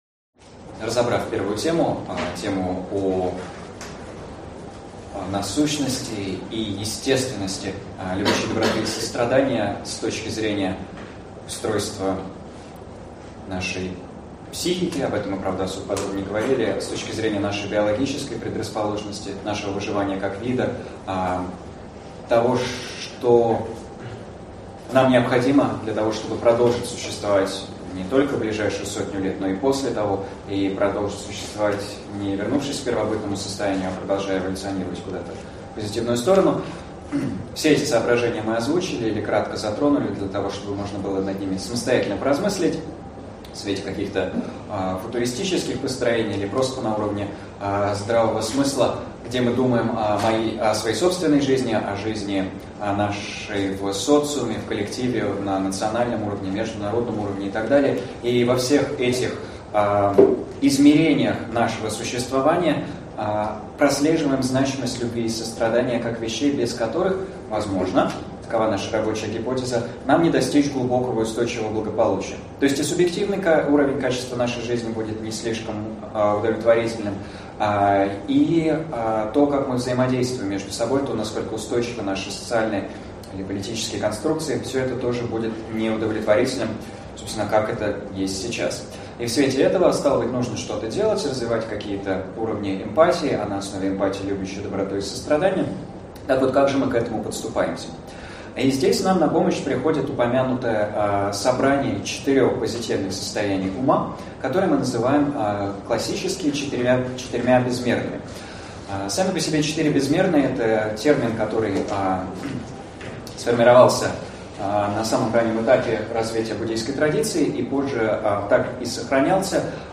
Аудиокнига Осознанная доброжелательность и четыре безмерных. Часть 2 | Библиотека аудиокниг